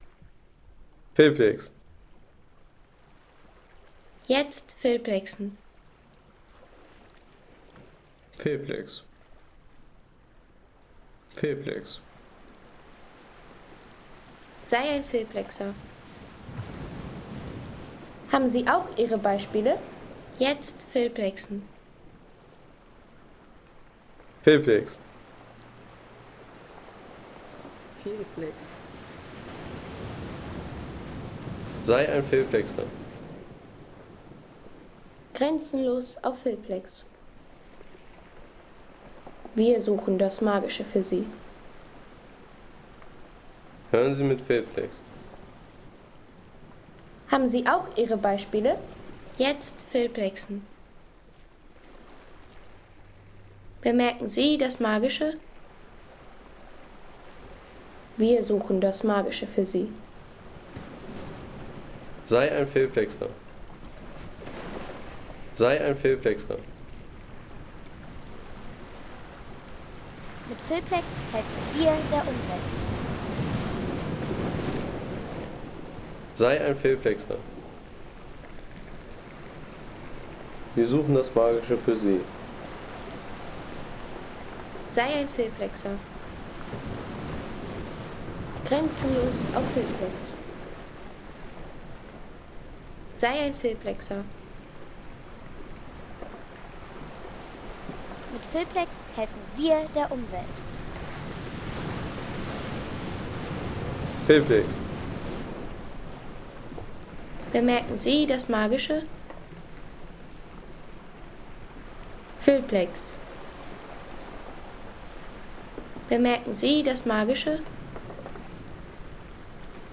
Strand auf Gran Canaria